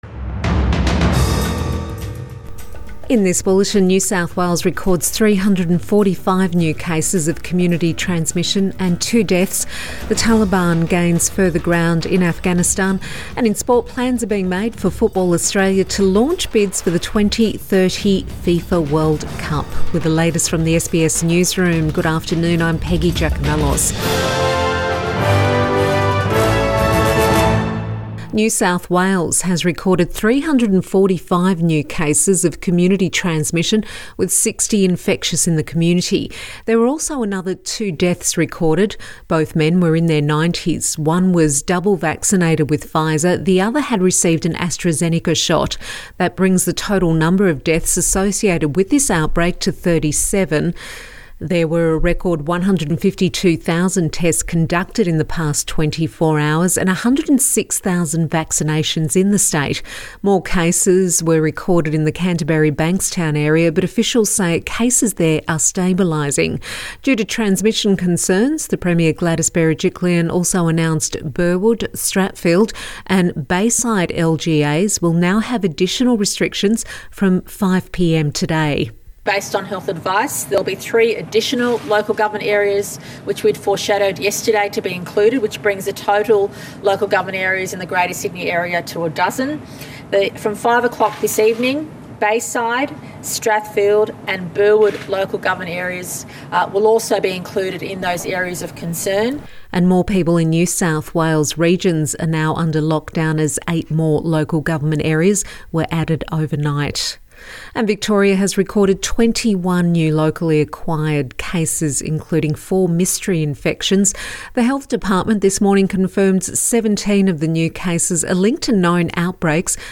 Midday bulletin 12 August 2021